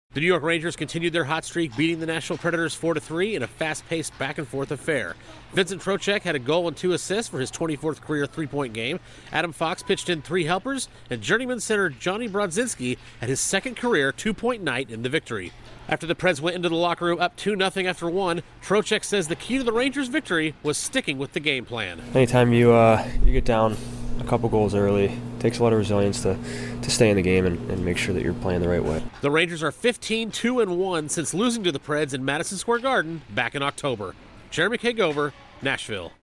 The Rangers struggle early before defeating the Predators. Correspondent